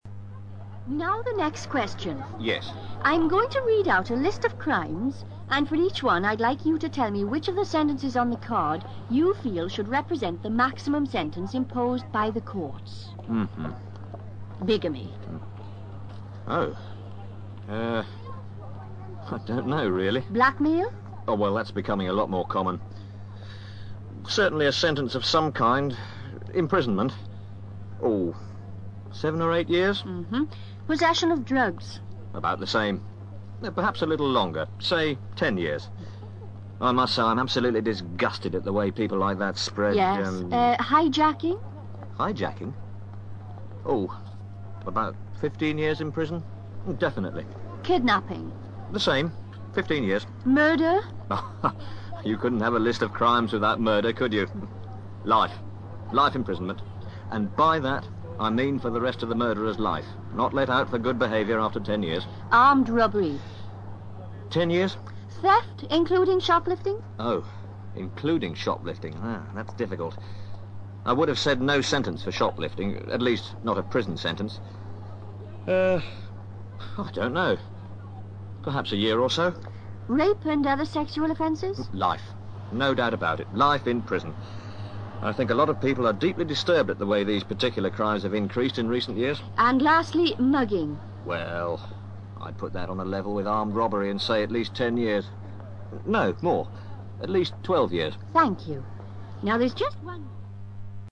ACTIVITY 75: You are going to hear two people (a man and a woman) being interviewed in the street as part of an opinion poll to discover the attitudes of the general public to certain social problems.
MAN